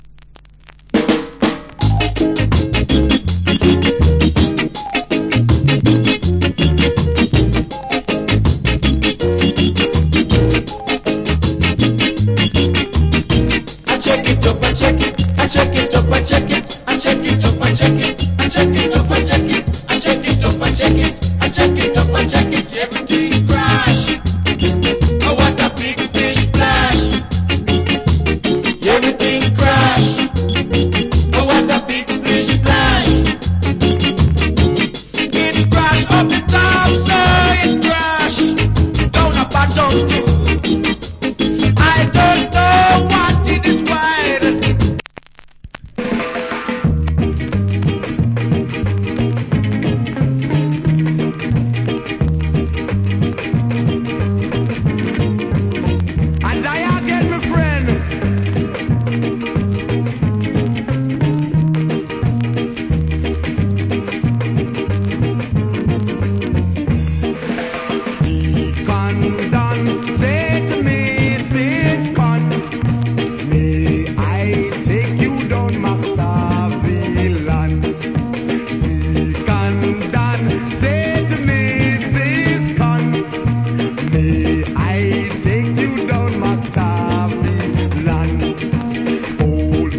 Boss Early Reggae Vocal.